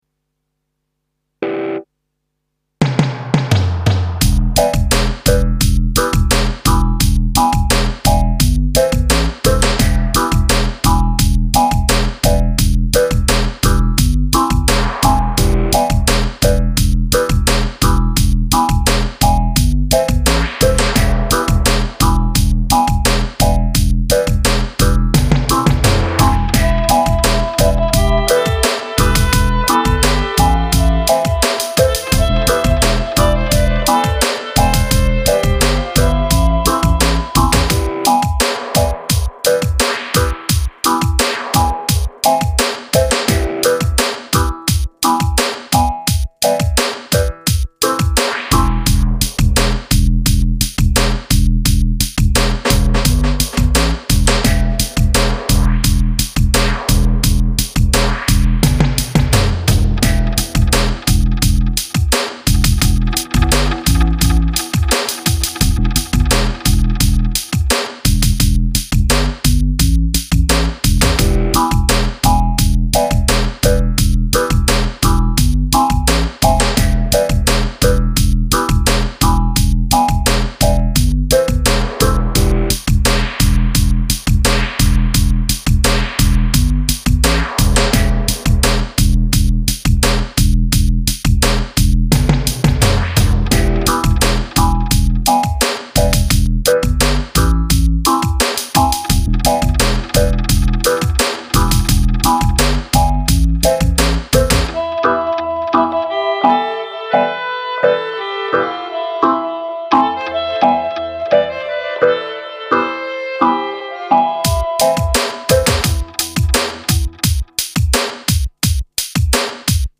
melodic dub
melodic-riddim-3Xx7D